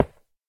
Sound / Minecraft / dig / stone2.ogg
stone2.ogg